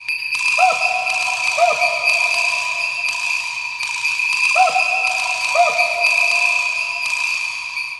描述：在这个自然循环中，有很多自然的东西。但灵感是外来的鸟，也许是啄木鸟，也许是猴子。
标签： 120 bpm Ambient Loops Percussion Loops 1.35 MB wav Key : Unknown
声道立体声